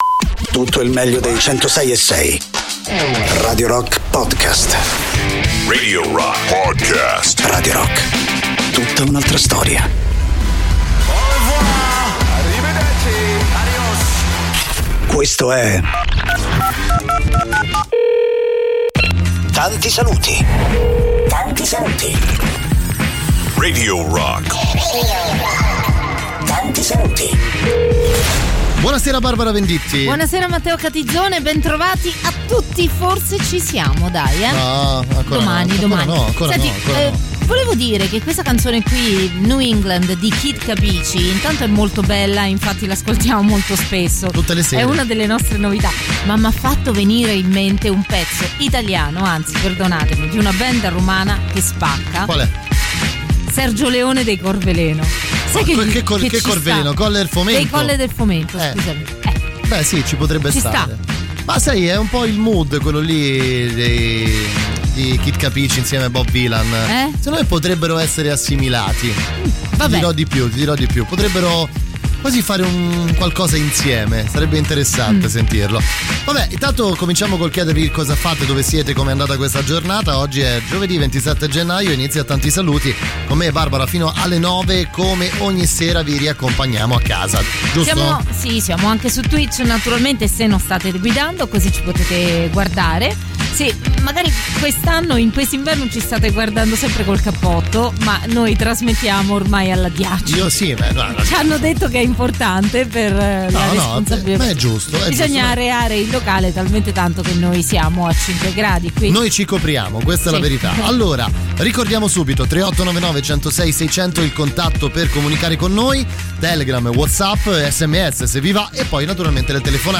in diretta dal lunedì al venerdì, dalle 19 alle 21, con “Tanti Saluti” sui 106.6 di Radio Rock.